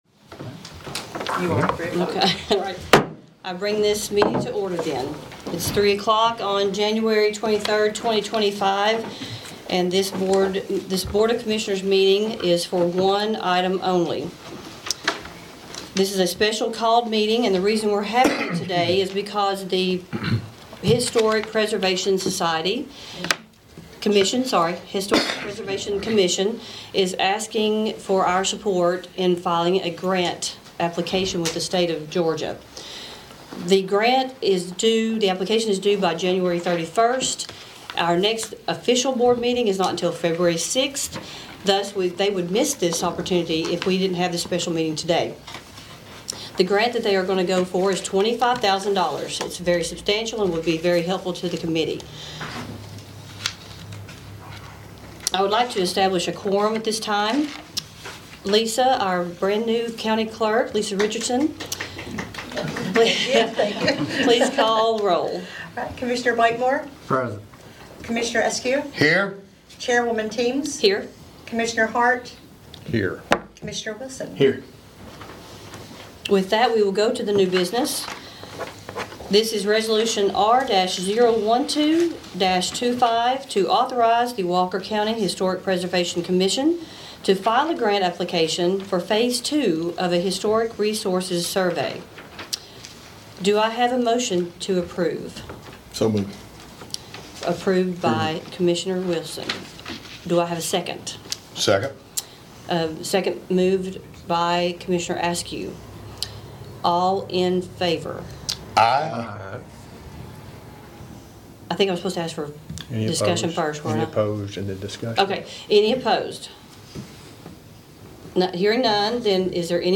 The Walker County Board of Commissioners held a special called meeting today to consider a resolution to apply for a historic resources survey grant.